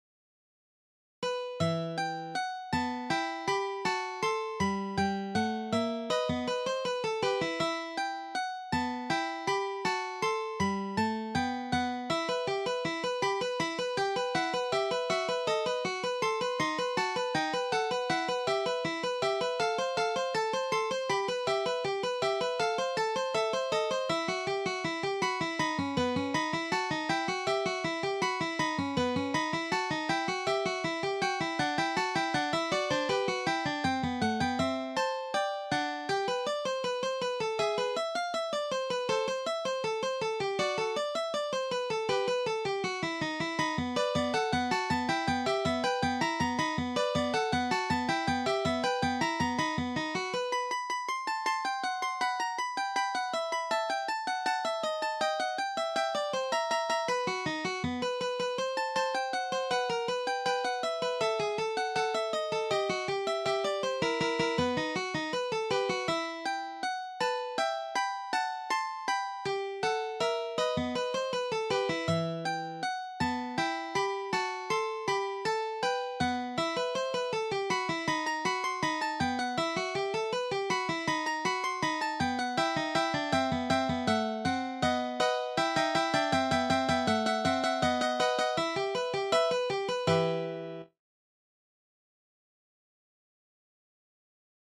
Duet No. 9 by Ferdinando Carulli is arranged for two guitars in the key of E minor. The extended range is high C, first string, eighth fret. Rhythms are advanced level, mostly sixteenth notes. There are many accidentals.